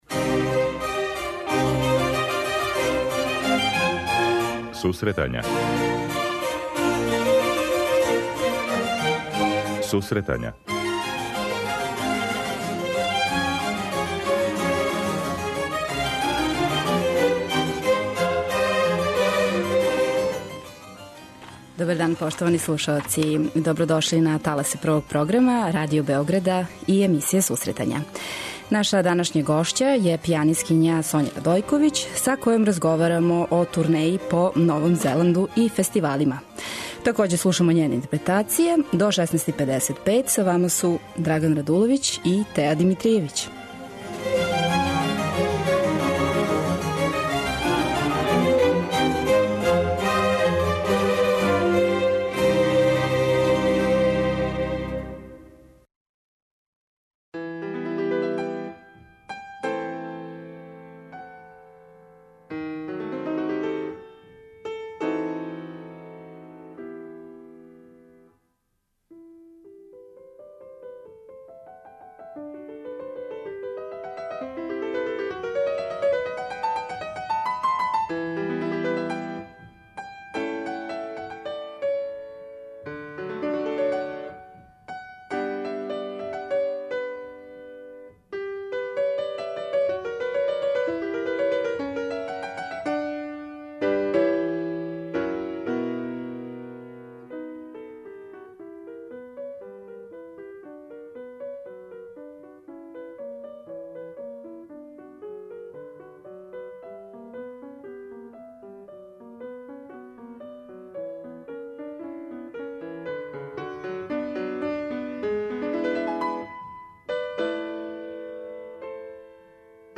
преузми : 25.96 MB Сусретања Autor: Музичка редакција Емисија за оне који воле уметничку музику.